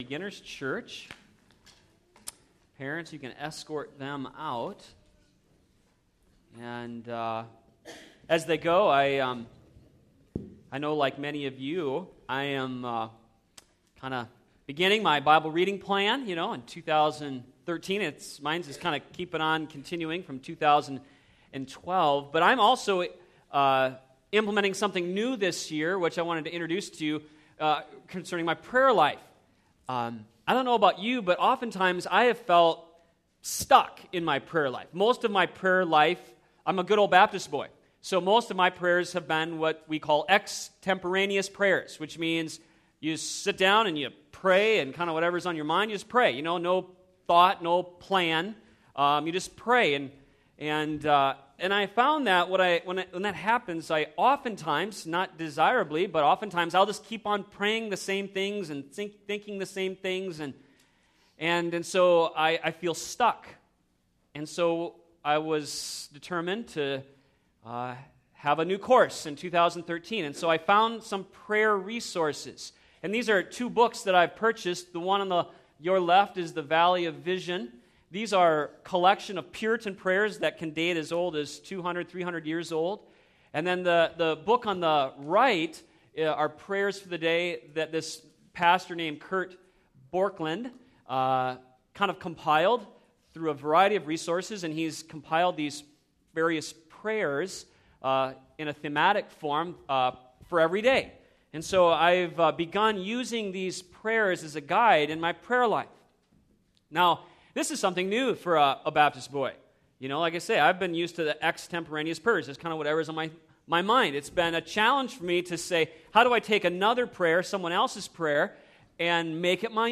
sermon1613.mp3